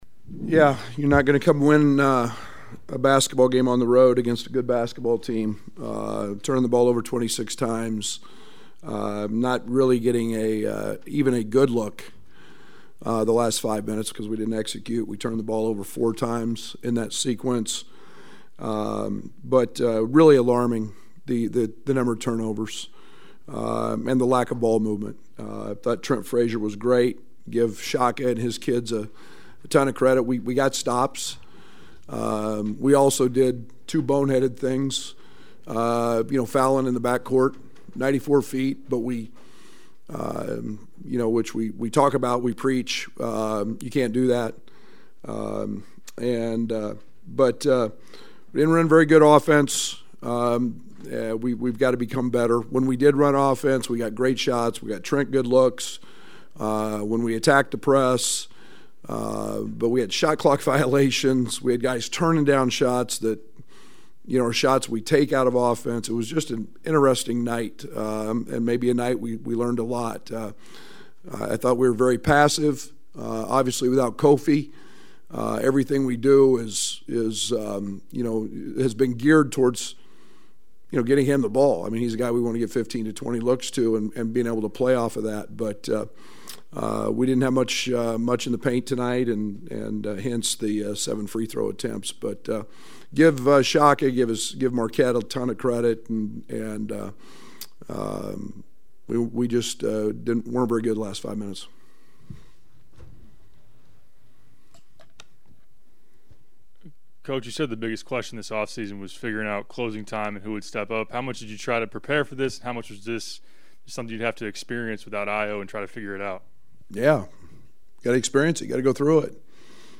FULL-Brad-Underwood-postgame-presser-at-Marquette.mp3